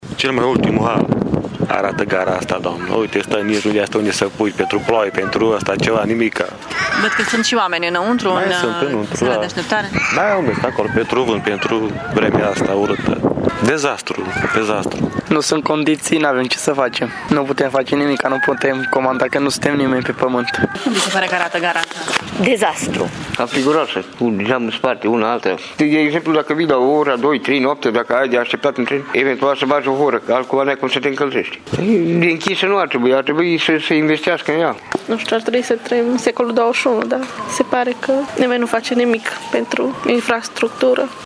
Câteva zeci de călători erau astăzi în Gara Mică din Târgu-Mureș. Oamenii sunt dezamăgiți de condițiile de aici și nu cred că lucrurile se vor schimba în bine prea curând :